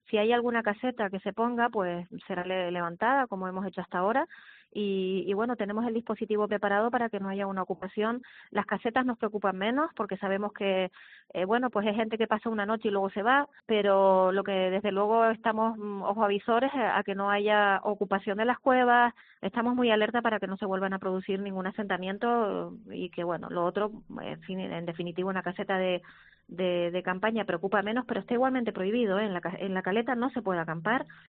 Isabel García, consejera de Medio Natural del Cabildo de Tenerife